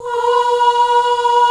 FEM 5 B3.wav